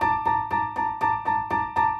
Index of /musicradar/gangster-sting-samples/120bpm Loops
GS_Piano_120-A2.wav